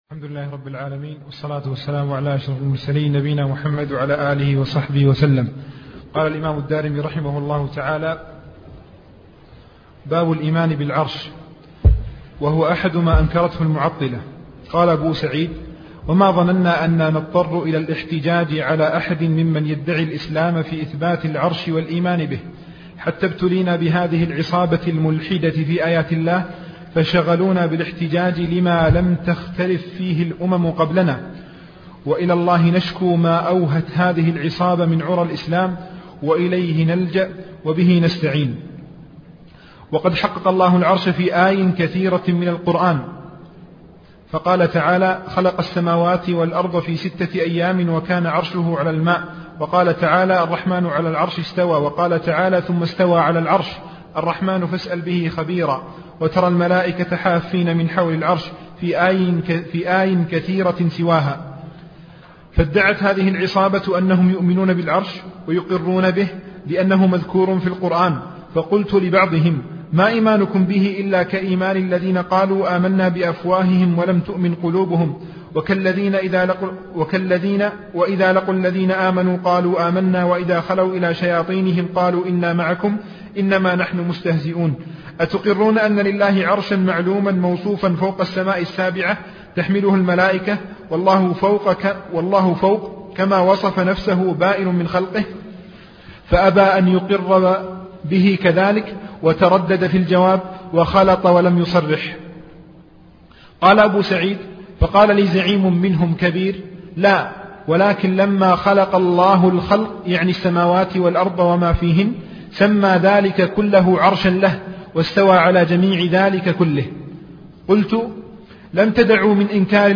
عنوان المادة الدرس (2) شرح رسالة في الصفات تاريخ التحميل الخميس 9 فبراير 2023 مـ حجم المادة 31.99 ميجا بايت عدد الزيارات 314 زيارة عدد مرات الحفظ 101 مرة إستماع المادة حفظ المادة اضف تعليقك أرسل لصديق